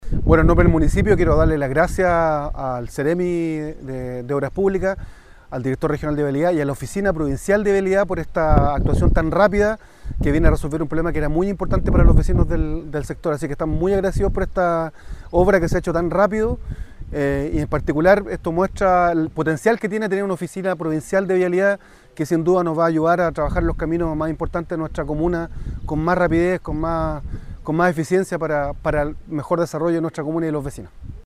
El alcalde de San Carlos, Rubén Méndez, destacó el trabajo conjunto entre las instituciones.
Ruben-Mendez-alcalde-San-Carlos.mp3